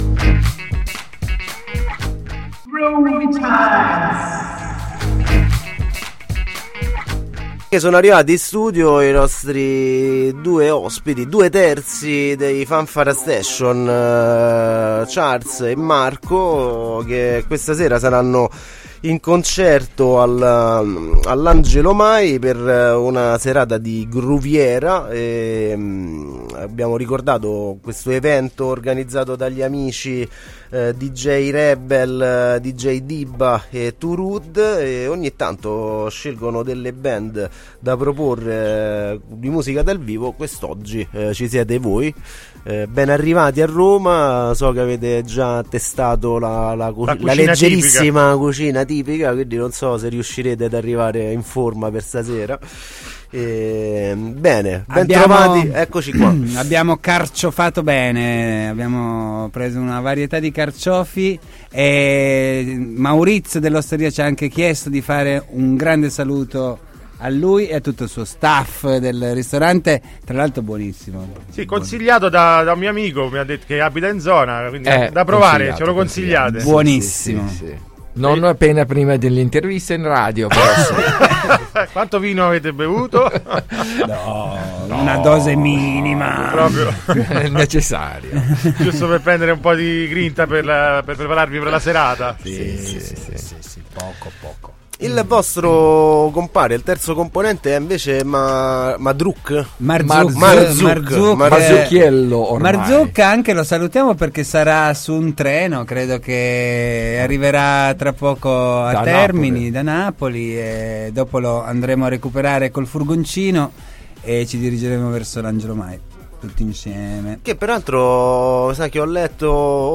Intervista ai Fanfara Station | Radio Città Aperta